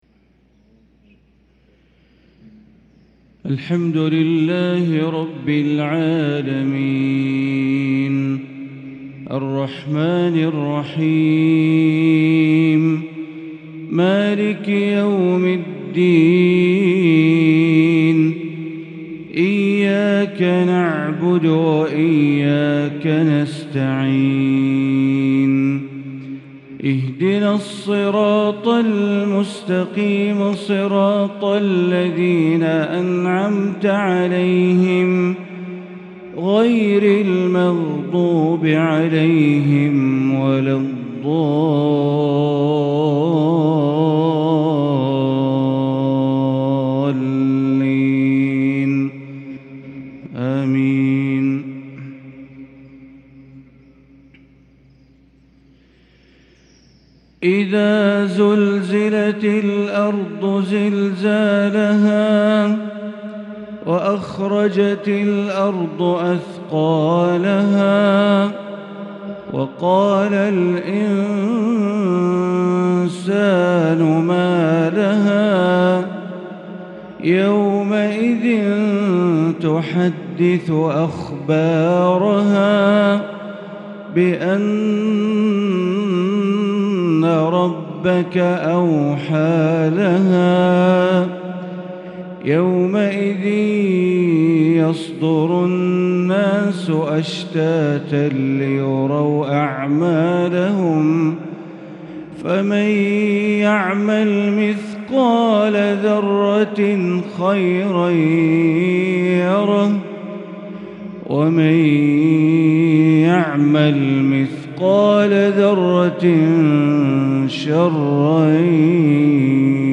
مغرب الخميس 4 شوال 1443هـ سورتي الزلزلة و العاديات | Maghrib prayer Surat Az-Zalzala & Al-Aadiyat 5-5-2022 > 1443 🕋 > الفروض - تلاوات الحرمين